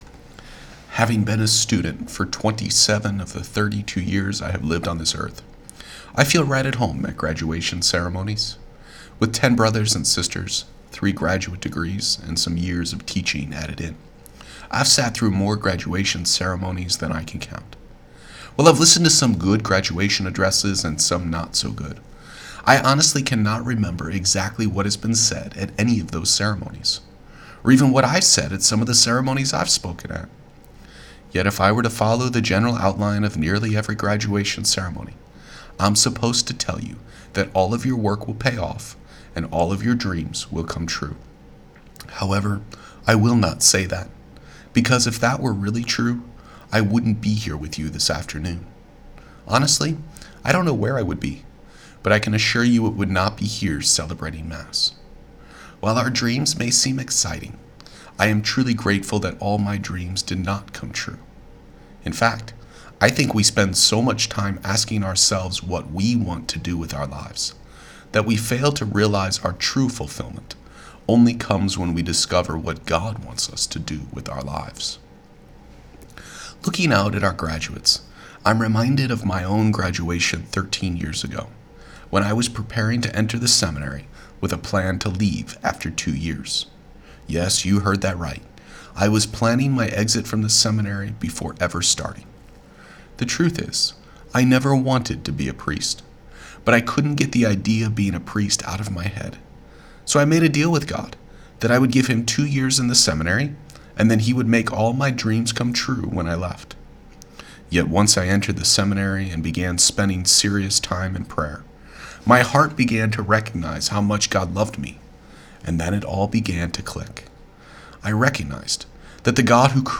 John Paul II Prep Class of 2020 Graduation Homily
Posted in Homily